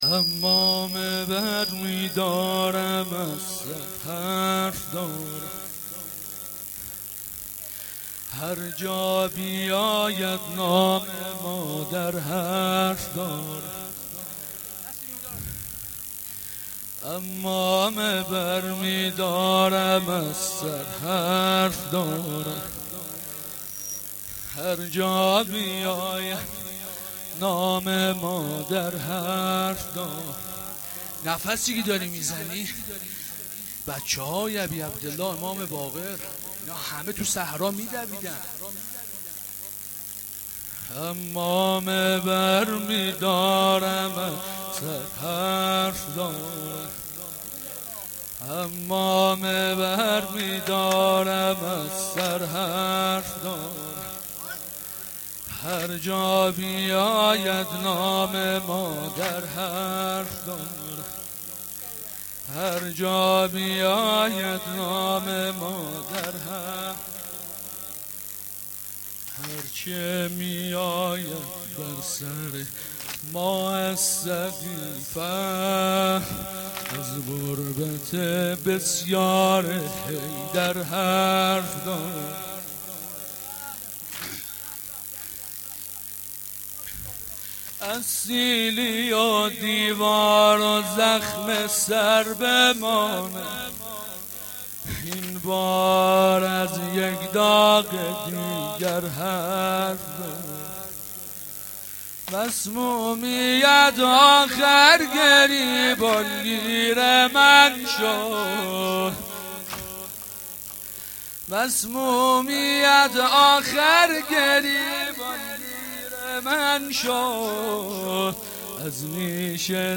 روضه العباس
sangin-Rozatol-Abbas.shahadat-emam-Bagher.mp3